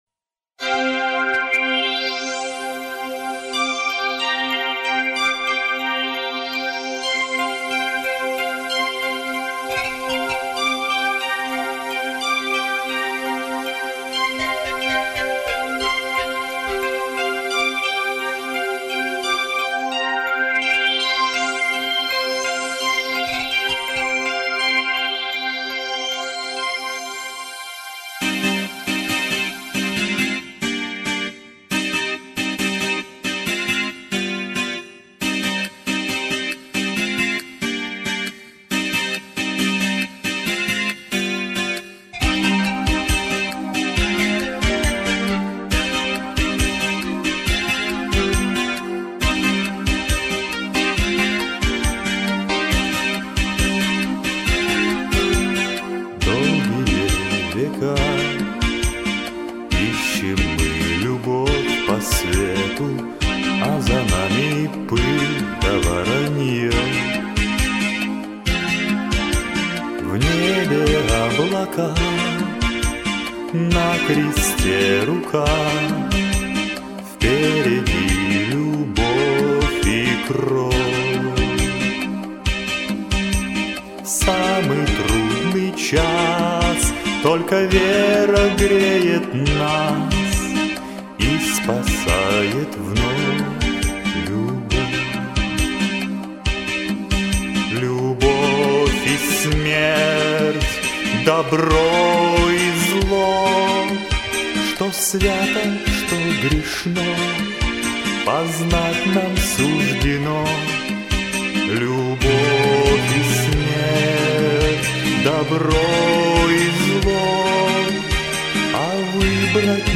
Я поэтому и выбрала мужское исполнение.... да ещё КАКОЕ!!!